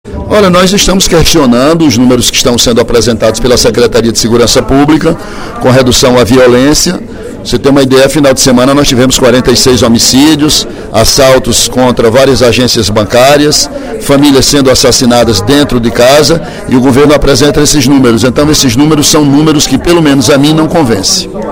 O deputado Ely Aguiar (PSDC) falou, durante o primeiro expediente da sessão plenária desta quarta-feira (01/04), sobre a onda de violência que “assola” o Ceará. O parlamentar criticou a atual gestão da Secretaria de Segurança Pública e Defesa Social (SSPDS), “que até agora não apresentou nenhum programa concreto visando a contenção da violência”.